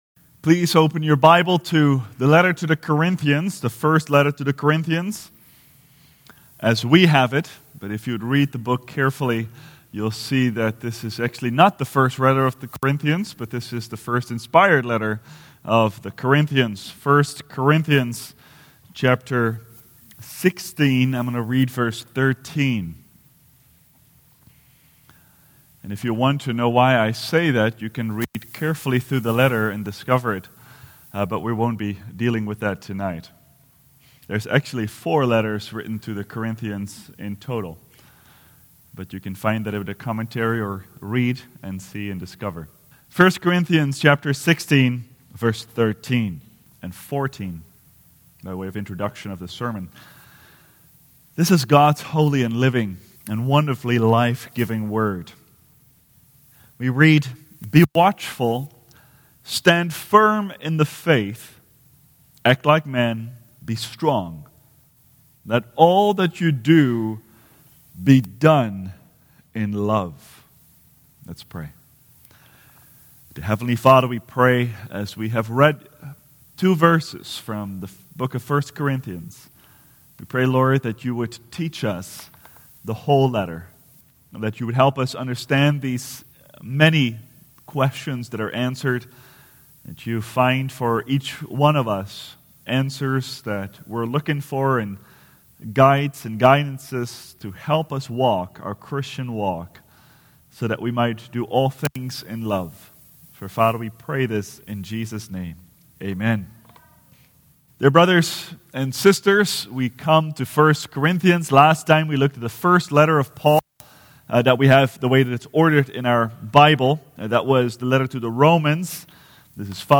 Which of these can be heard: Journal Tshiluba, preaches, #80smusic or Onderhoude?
preaches